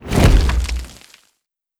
Explosion4.wav